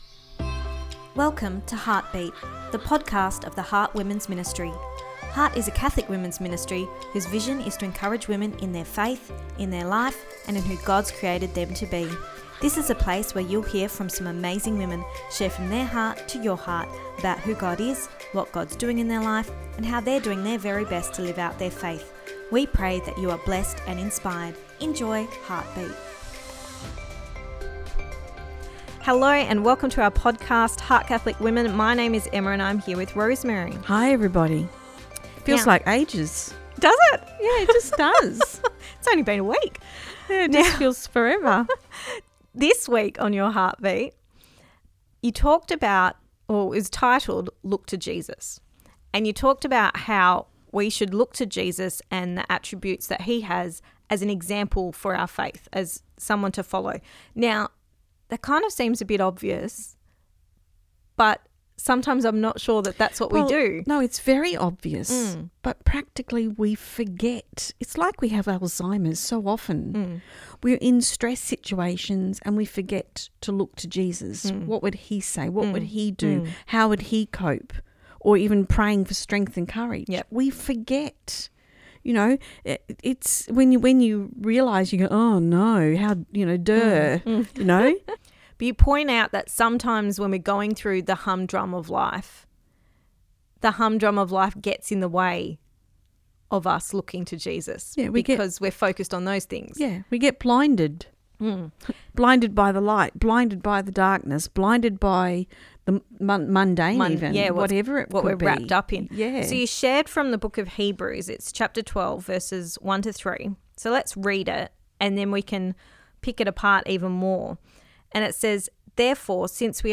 episode-147-look-to-jesus-part-2-our-chat.mp3